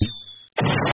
Special_Bomb_New.mp3